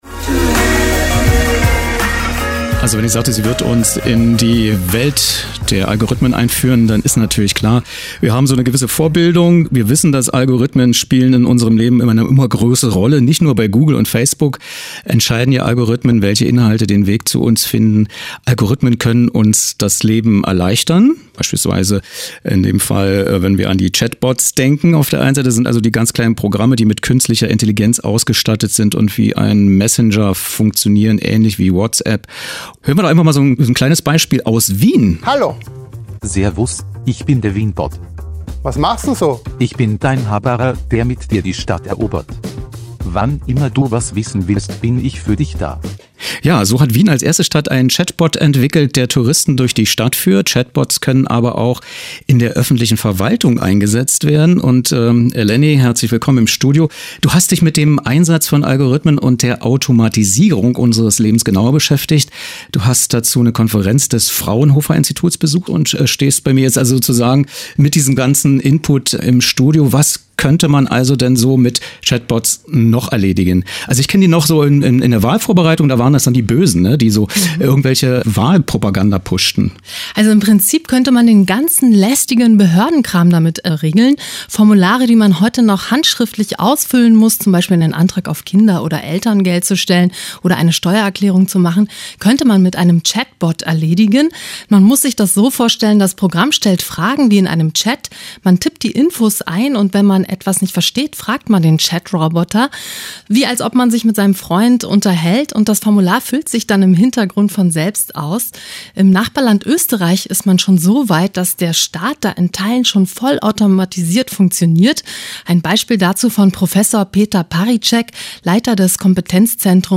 • Wien-Chatbot
• Anke Domscheit-Berg, Bundestagsabgeordnete, Die Linke
Was: Studiogespräch
Wo: Potsdam-Babelsberg, radioeins-Sendestudio